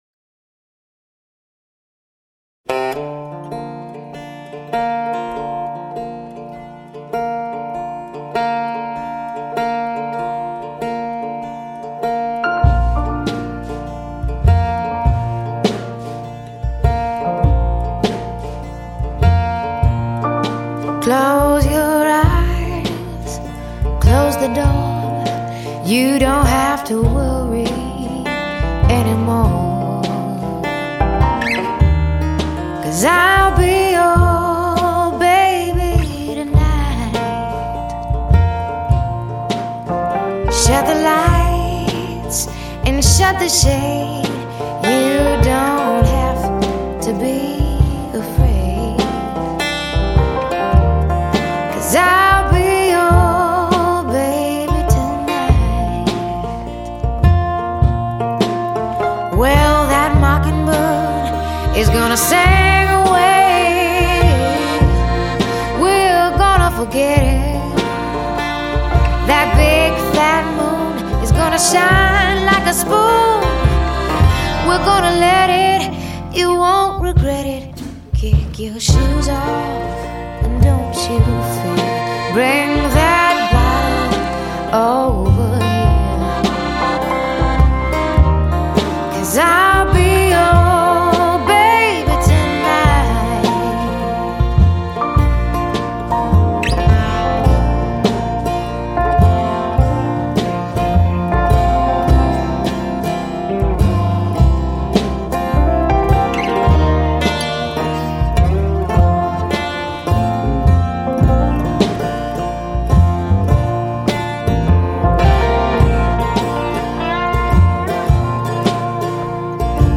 音乐类型：爵士乐
慵慵懒懒的爵士轻乐,放松心思,一杯咖啡,作沙发聆听....